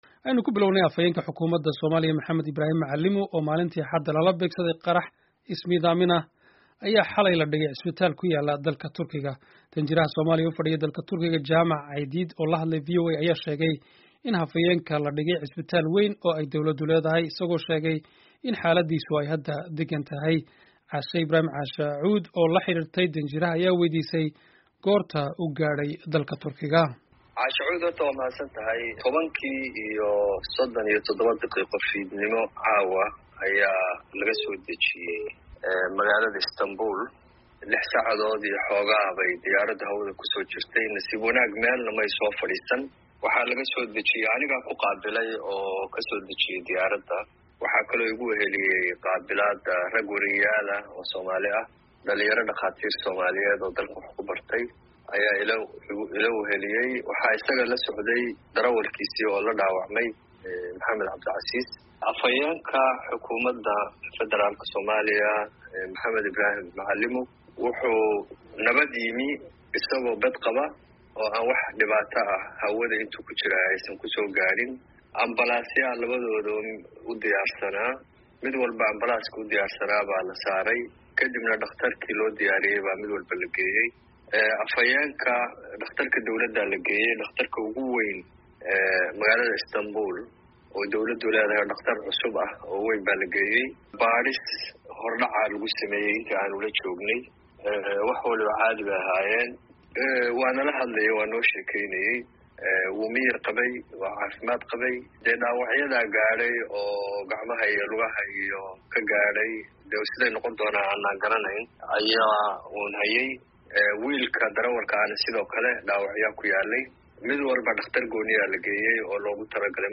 Wareysi: Safiirka Soomaaliya ee Turkey oo ka hadlay xaaladda Macalimuu